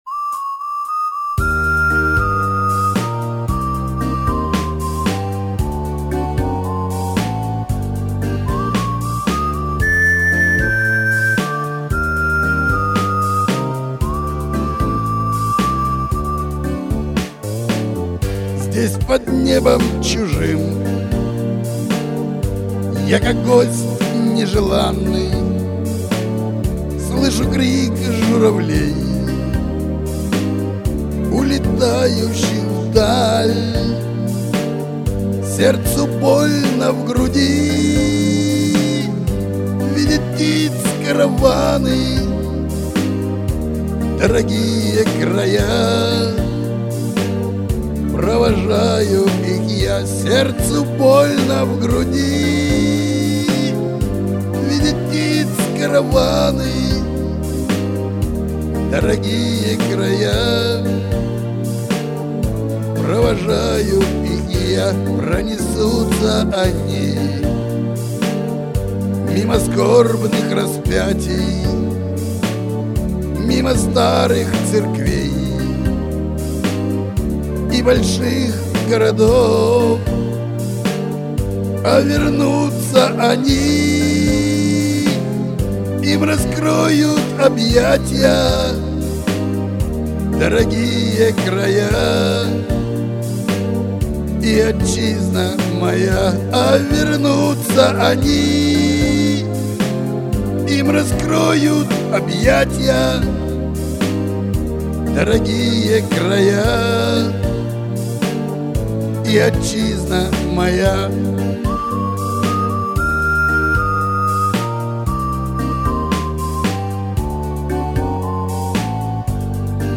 НЕ ЧЕТА МОЕЙ ХРИПОЦЕ